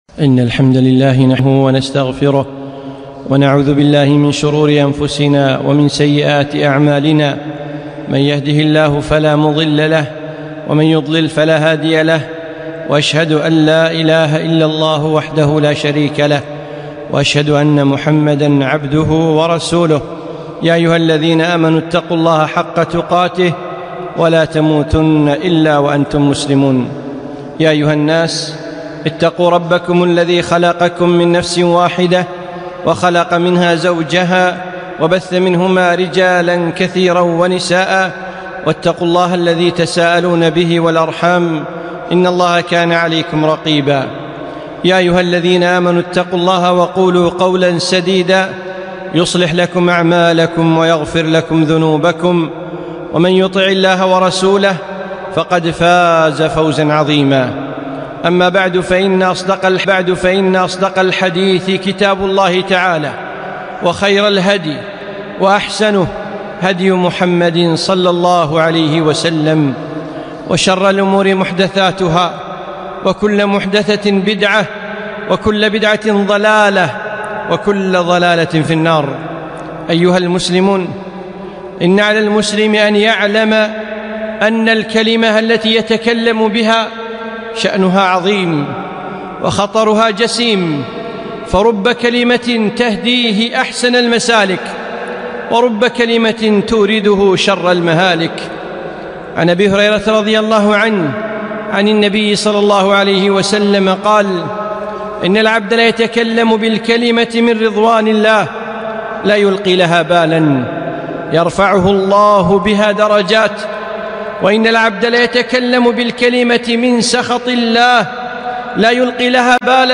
خطبة - خطر الإشاعات في هدم المجتمعات